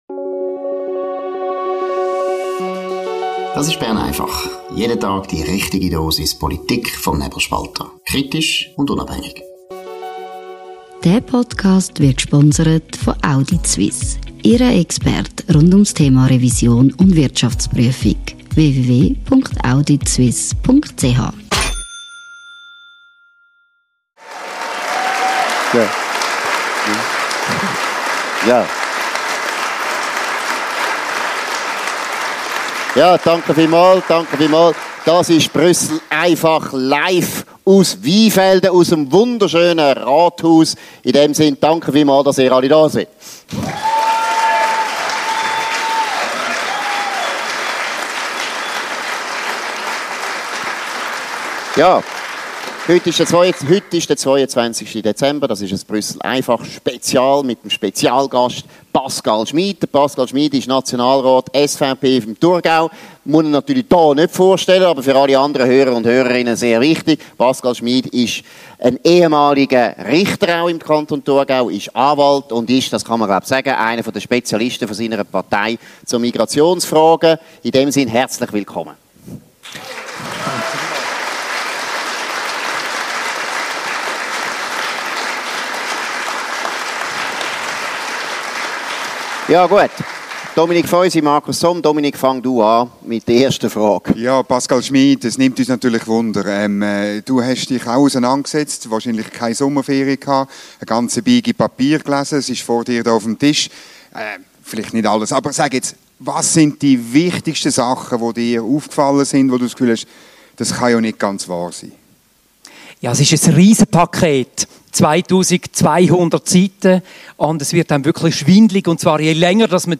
Das Gespräch wurde live im Rathaus in Weinfelden aufgezeichnet.